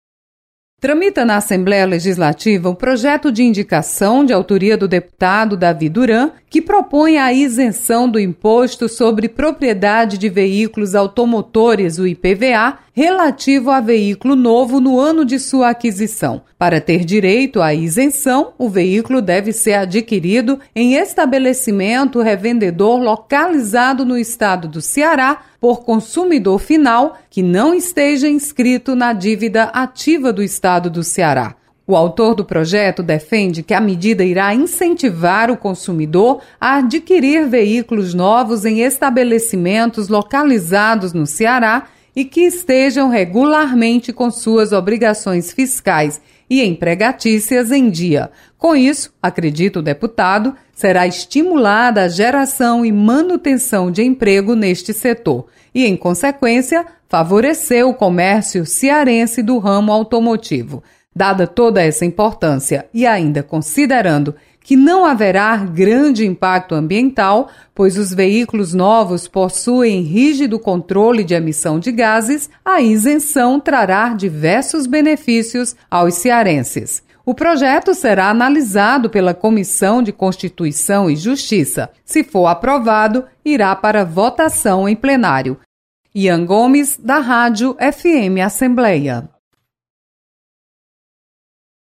Isenção de IPVA para compra de carro zero quilômetro é proposta em projeto de indicação. Repórter